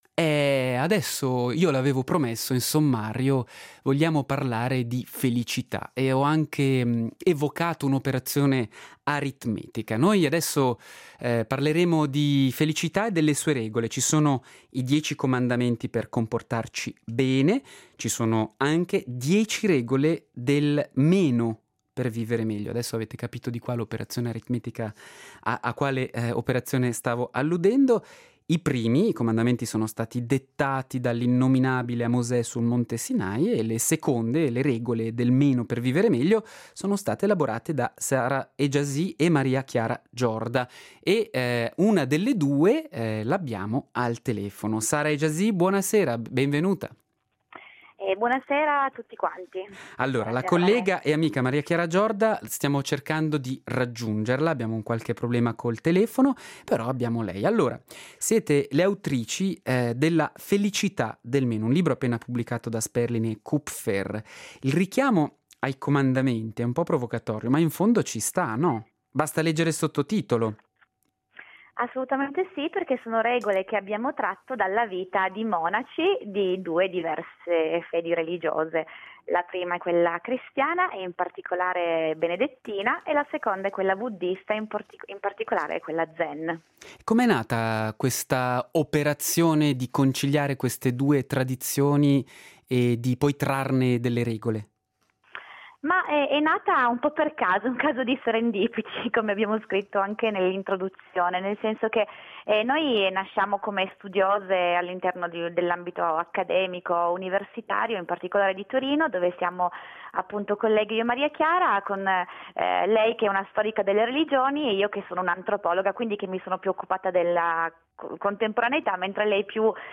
"La Felicità del meno: Le dieci regole dei monaci per vivere meglio" Disanima con le autrici di questo libro pubblicato da Sperling&Kupfer